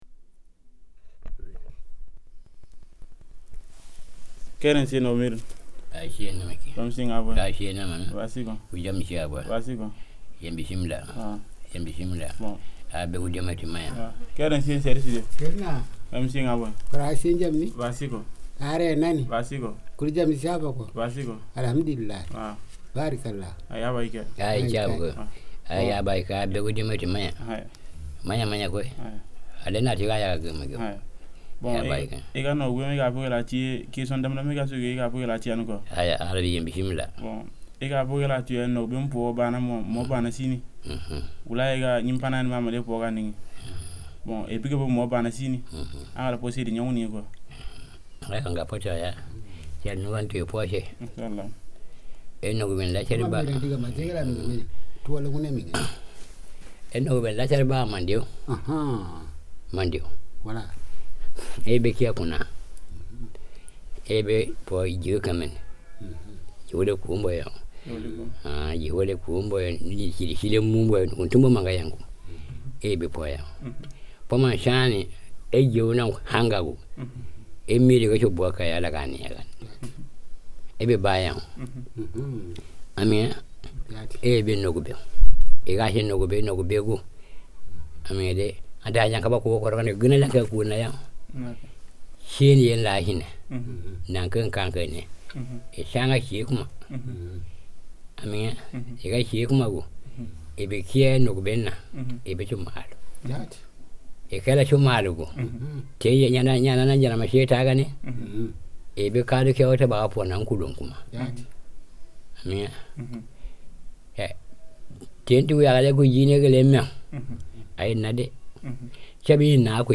• field recordings in mp3 format made with digital microphone in Namagué village, Mali.